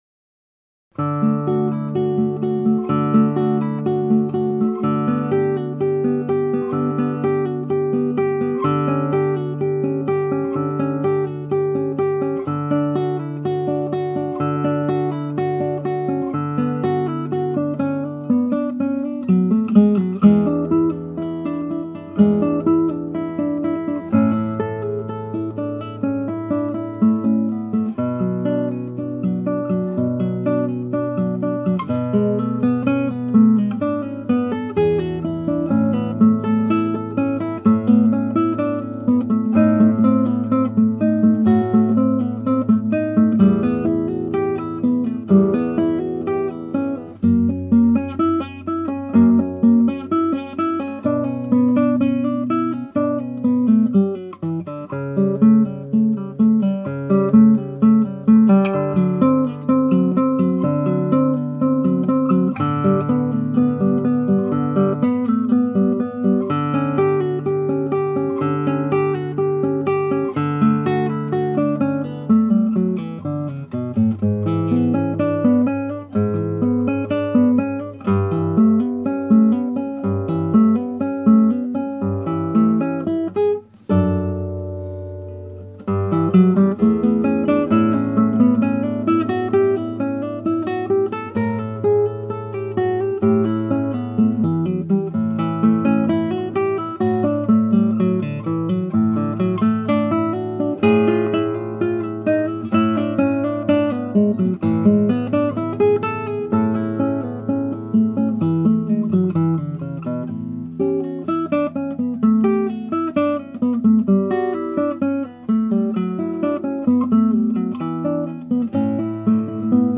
(アマチュアのクラシックギター演奏です [Guitar amatuer play] )
原調はト長調ですがギター版はニ長調です。
テンポは前回よりも少し速めにした。弾きこみの成果で全体としてはスムーズに弾ける様になったが曲想を込めるまでには至らなかった。